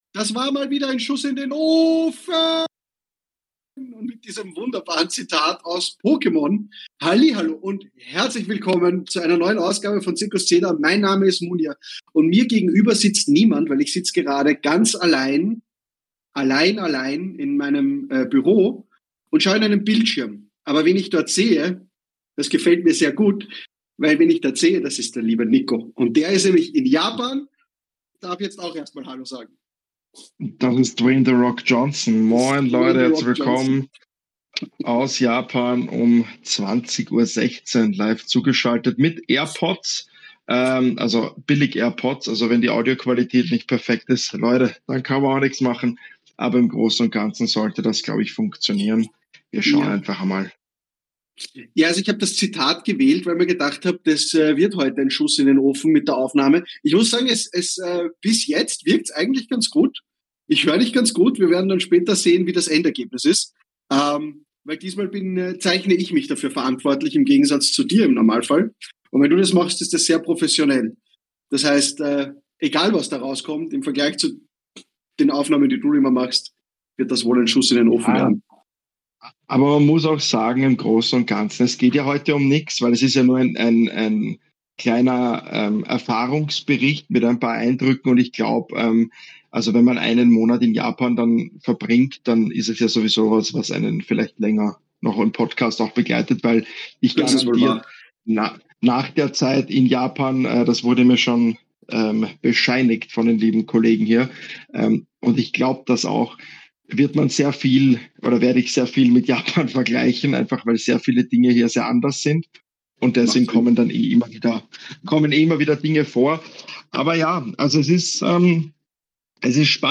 Wir wünschen euch eine gute Zeit beim zuhören und freuen uns, dass ihr uns auch treu bleibt, wenn die Aufnahme so klingt als hätte ich in meine Mikrowelle hineingeredet. Das Internet hat eben nicht die ganze Zeit mitgespielt, da müssen wir nun alle durch.